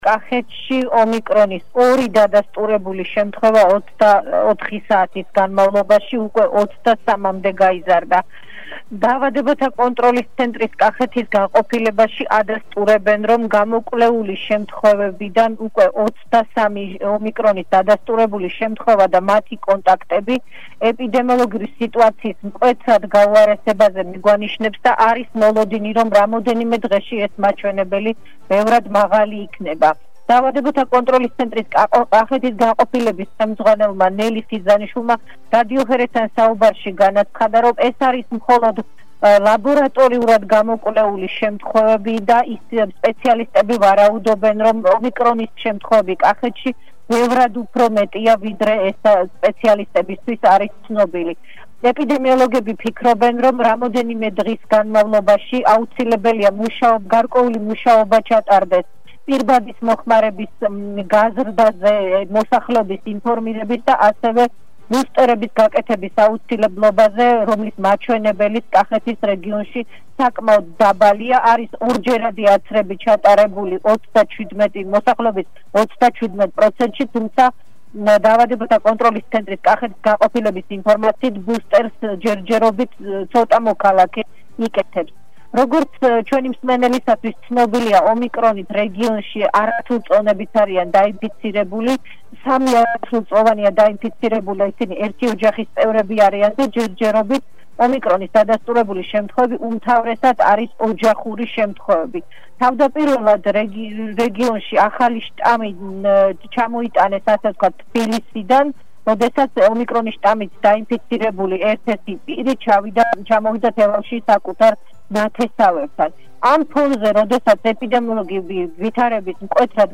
მოუსმინეთ ჩვენი კორესპონდენტის